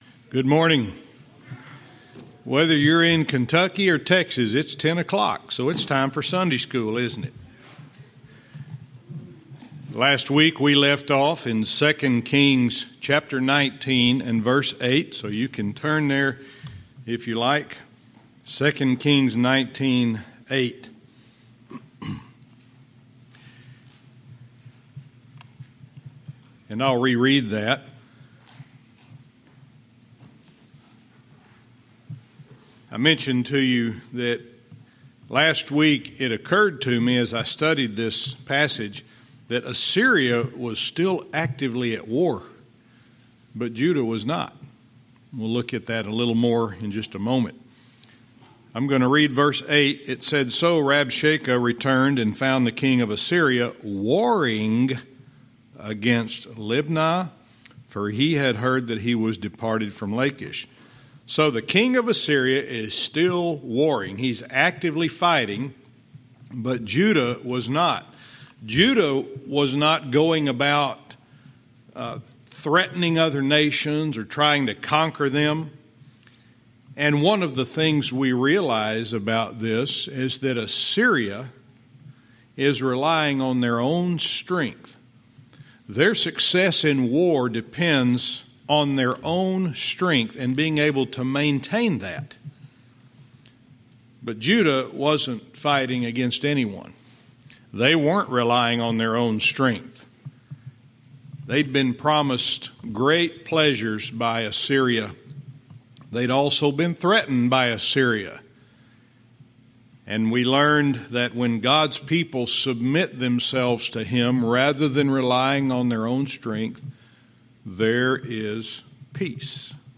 Verse by verse teaching - 2 Kings 19:8-14